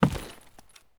46265b6fcc Divergent / mods / Soundscape Overhaul / gamedata / sounds / material / human / step / new_wood1.ogg 35 KiB (Stored with Git LFS) Raw History
new_wood1.ogg